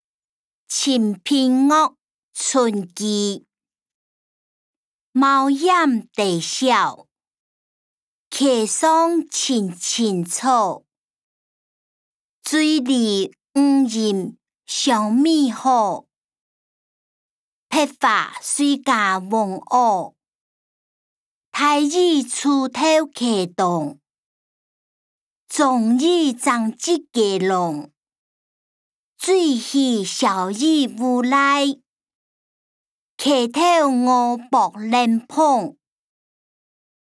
詞、曲-清平樂：村居 音檔(饒平腔)